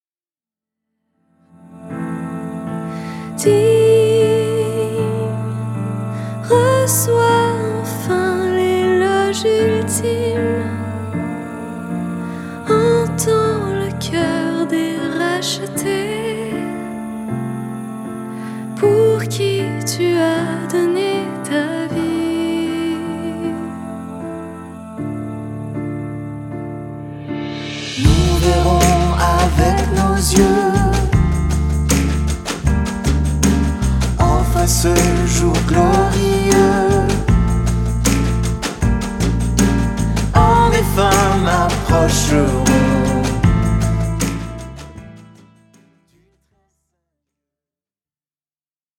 Une pop-Louange actuelle à la fois profonde et dansante